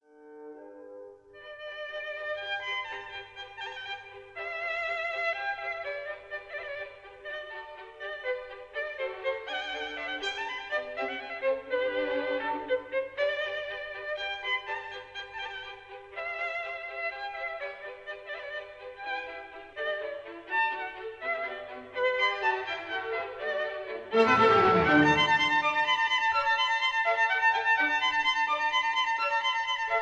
This is a 1958 stereo recording